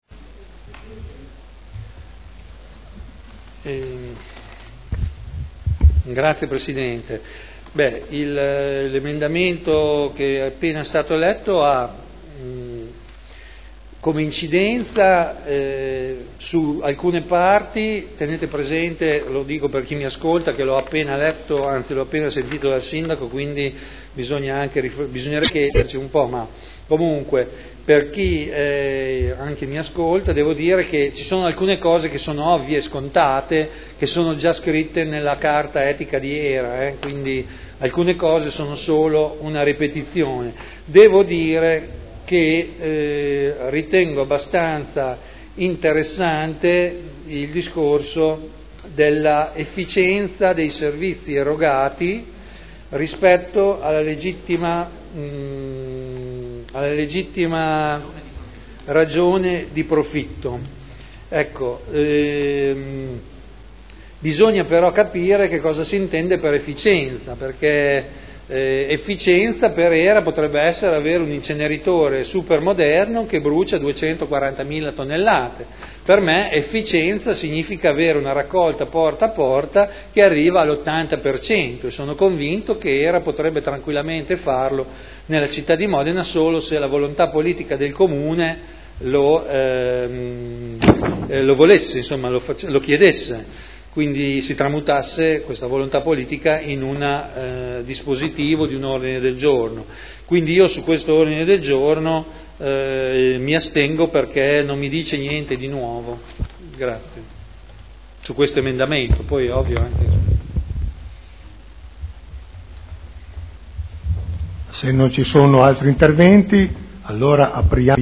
Seduta del 06/06/2011. Mozione presentata dai consiglieri Barcaiuolo, Taddei, Galli, Pellacani, Morandi, Bellei, Vecchi, Santoro (PdL) avente per oggetto: "Trasparenza HERA". Intervento sull'emendamento